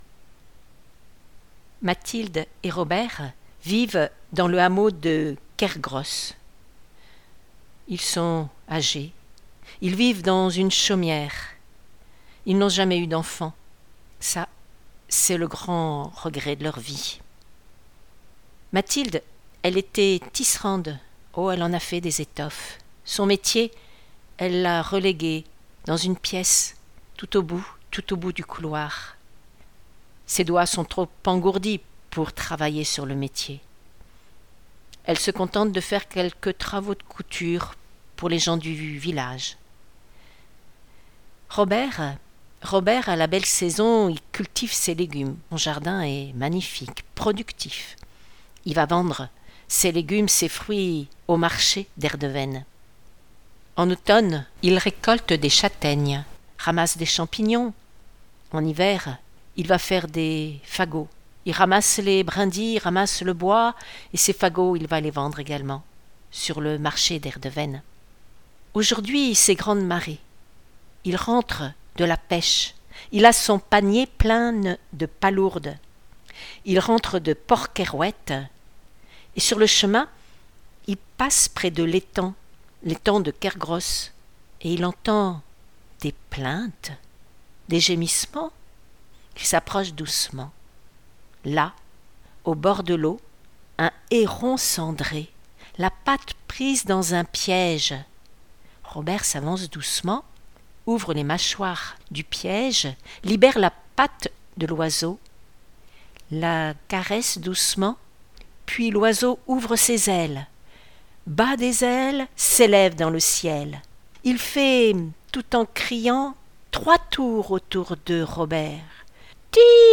L’association Histoires de mots vous propose 3 contes audio pour une immersion totale dans les paysages vécus et contés d’Erdeven.